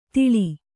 ♪ tiḷi